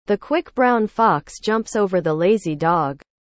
I heard the voice of a digital angel. She sounded a lot more natural than any of the previous versions I've had.
google_speech "The quick brown fox jumps over the lazy dog"